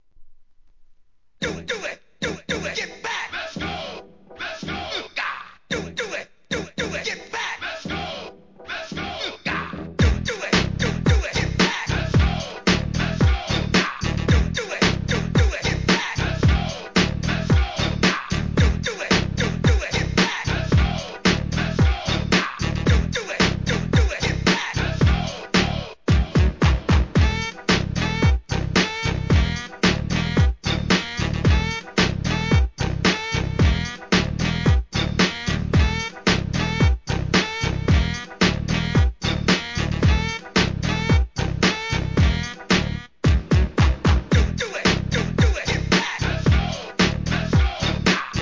HIP HOP/R&B
BPM 100 UPのノリノリ・ブレイクビーツを中心に10 INSTRUMENTALSとDJ TOOL収録!!